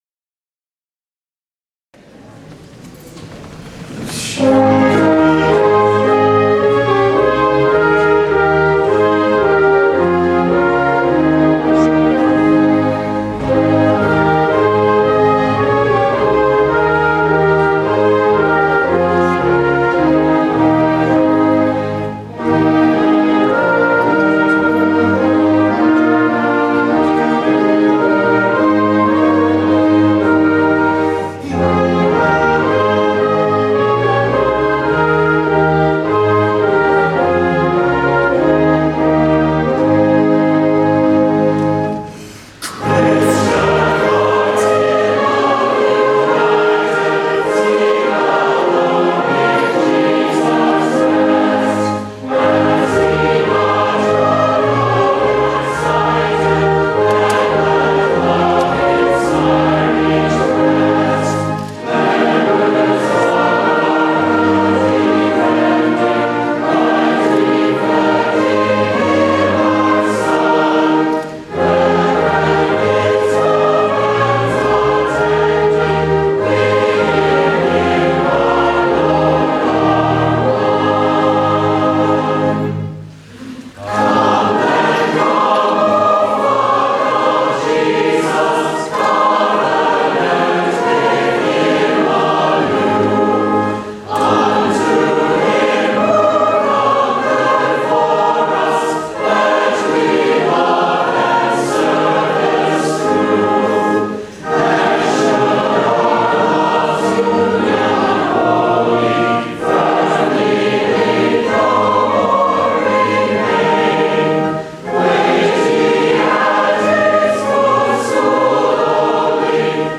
(8) Hymn (Herrnhut): Christian Hearts in Love United